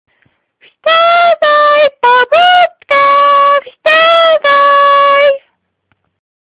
Audiobook "Pobudka"Słuchaj